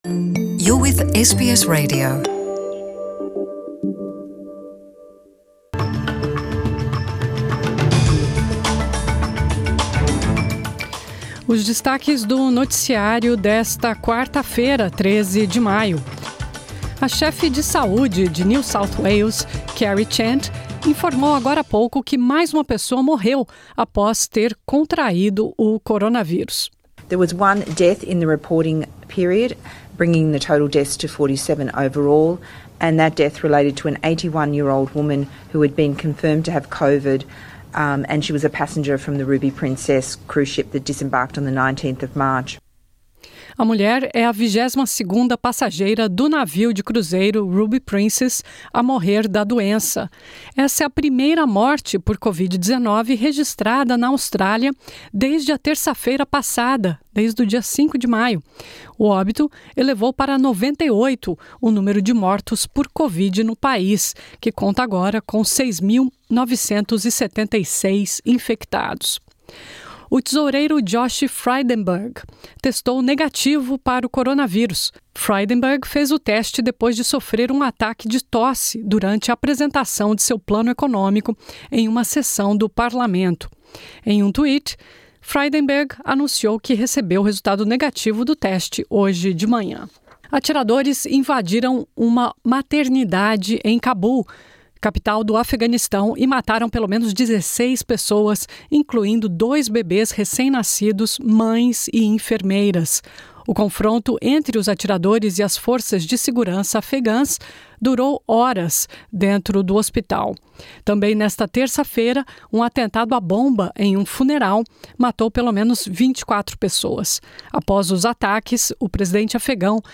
Mais uma morte por Coronavírus na Austrália, o 22° óbito ligado ao navio de cruzeiro Ruby Princess. Ouça essa e outras notícias do noticiário de hoje.